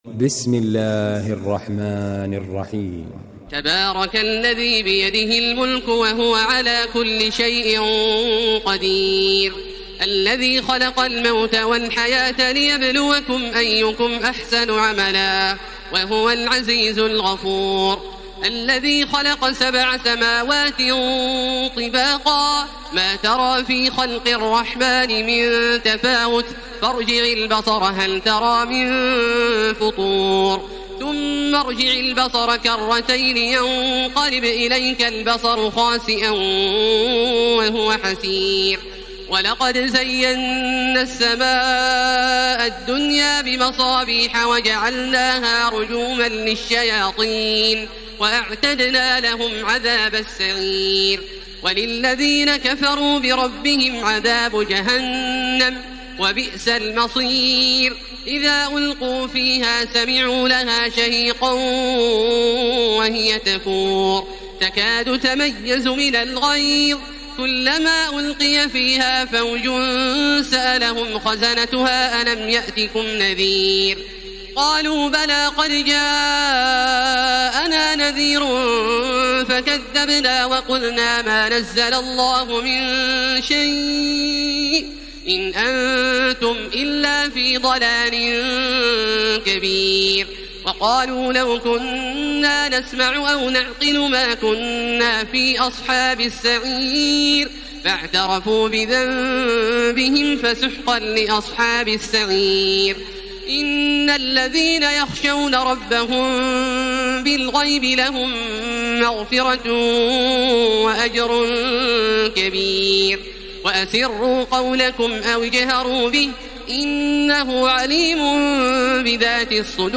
تراويح ليلة 28 رمضان 1433هـ من سورة الملك الى نوح Taraweeh 28 st night Ramadan 1433H from Surah Al-Mulk to Nooh > تراويح الحرم المكي عام 1433 🕋 > التراويح - تلاوات الحرمين